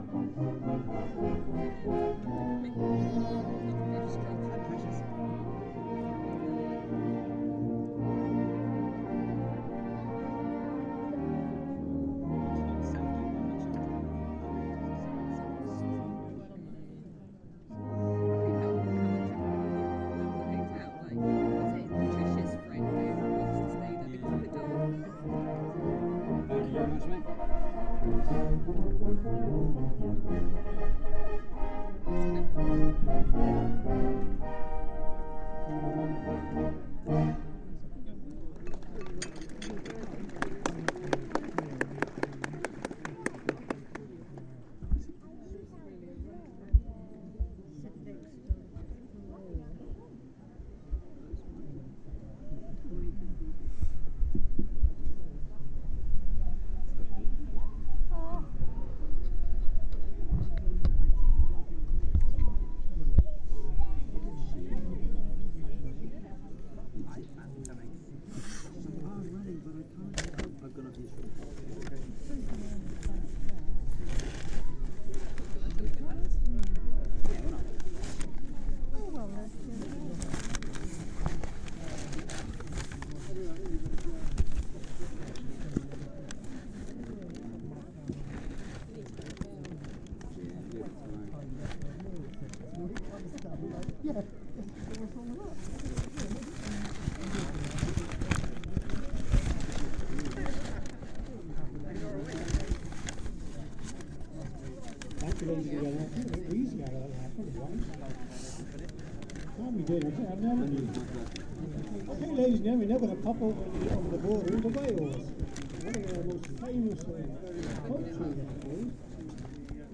Cinderford Band 2. End of Sea-Song Medley and All Through the Night. Sorry about rustling
Played in Priory Park, Malvern, as part of the Bands in the Park series of concerts three weeks ago